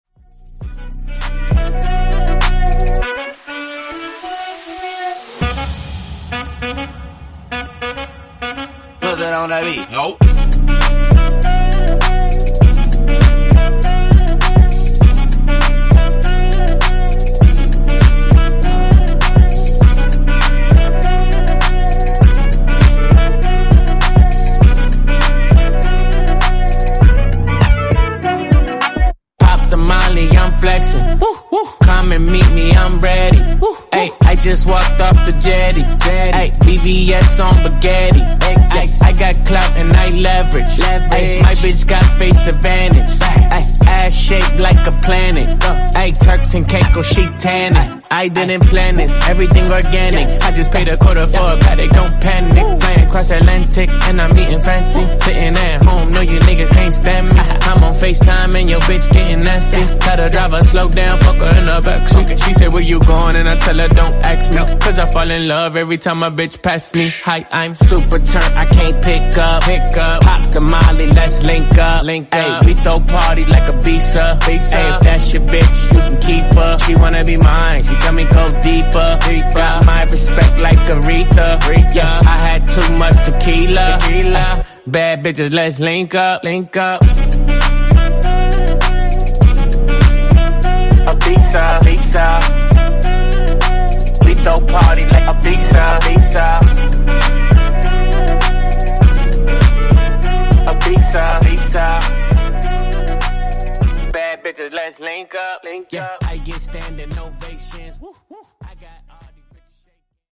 Genres: RE-DRUM , REGGAETON
Clean BPM: 85 Time